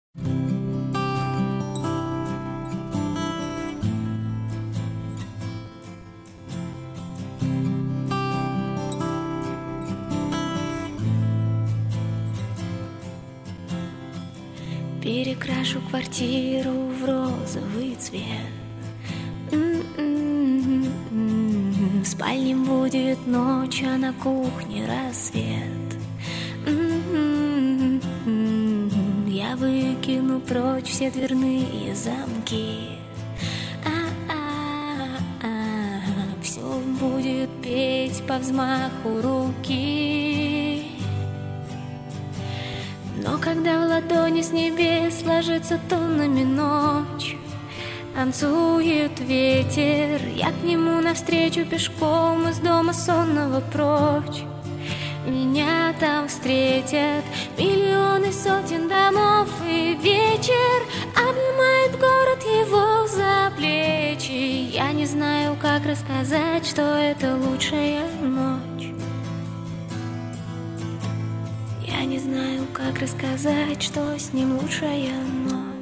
Pop
бас гитара
барабаны
композиции в стиле босса нова, и песни с  настроением рока.